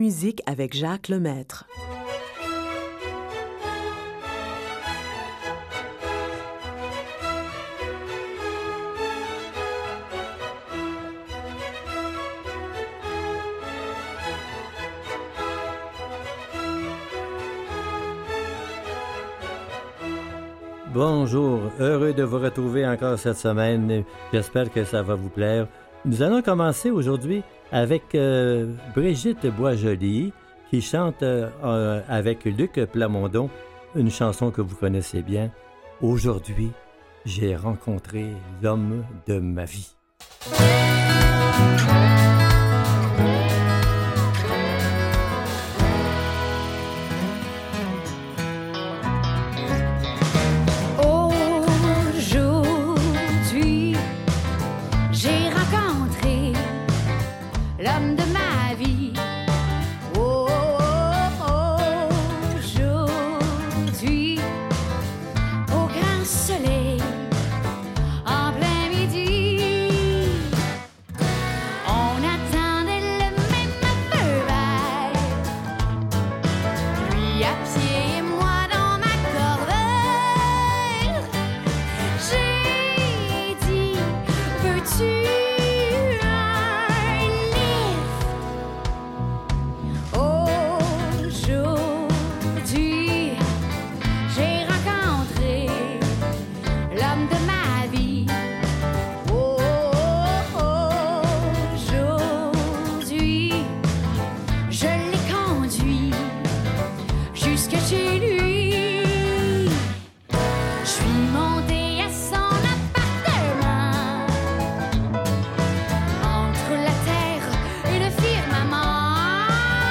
une toute nouvelle sélection musicale variée